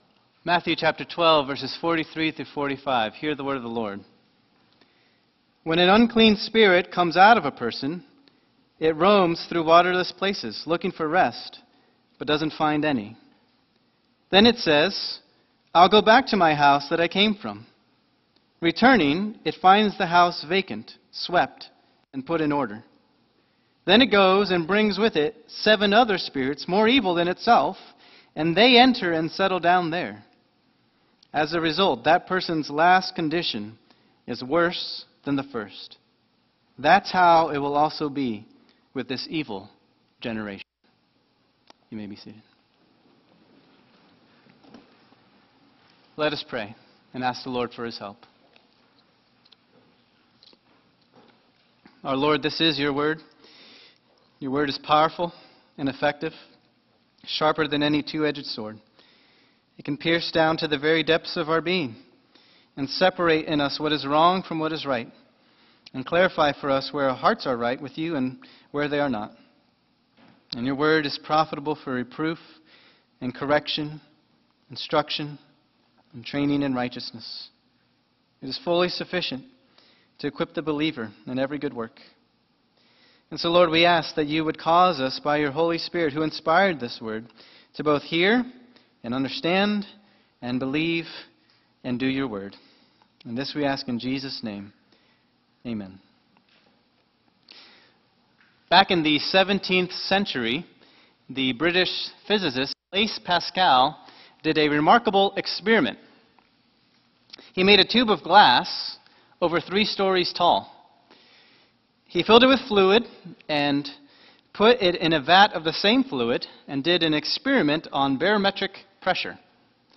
Sermon
2025 at First Baptist Church in Delphi, Indiana.